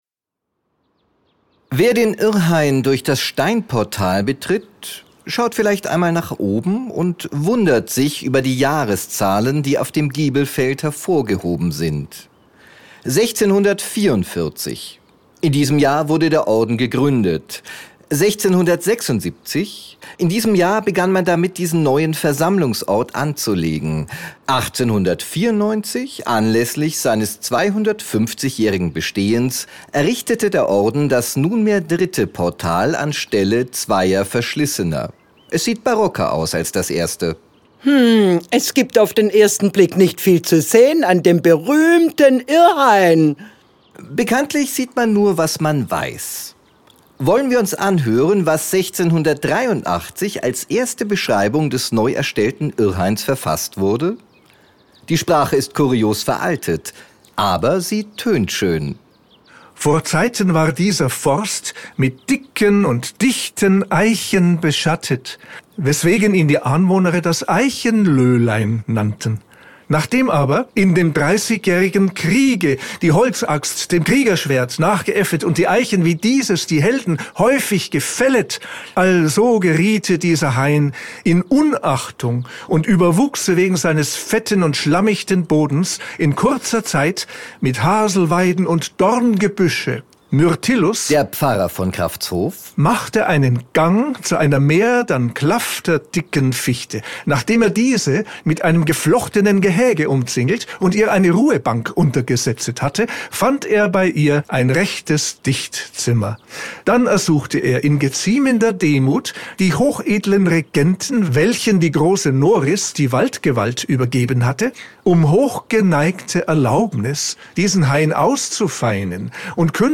Diese kurzen Hörspiele (max. 10 Minuten) sind von Mitgliedern des Blumenordens, die in den zwanziger Jahren dieses Jahrhunderts leben oder noch gelebt haben, geschrieben und gesprochen, gespielt oder musikalisch vorgetragen.